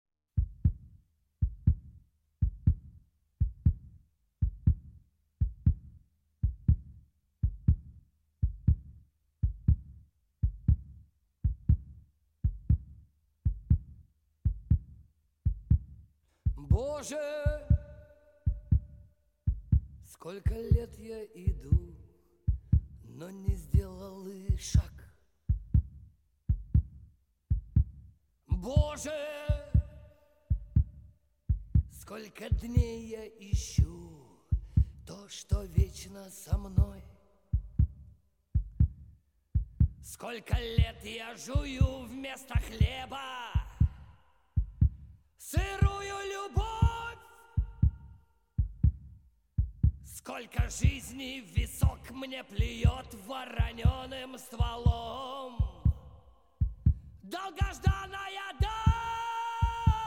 Рок
бескомпромиссные, жёсткие, ироничные, динамичные